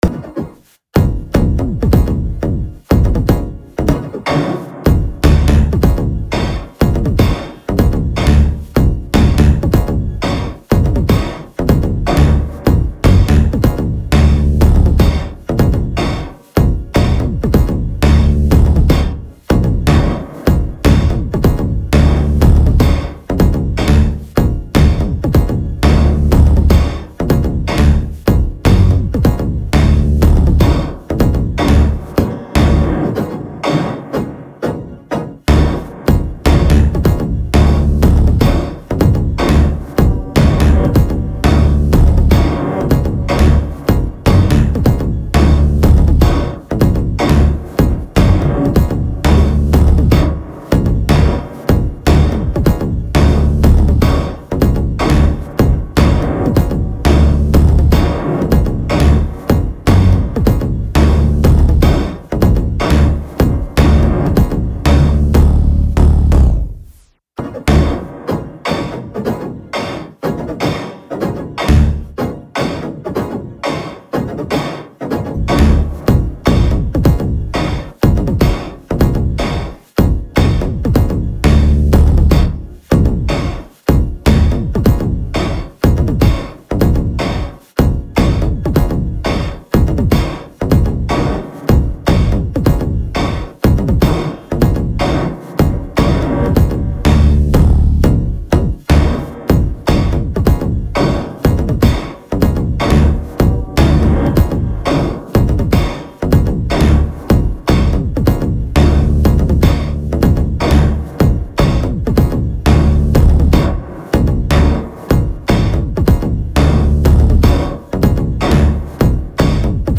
вот такую петрушку с помощью Moodal и Endless намутил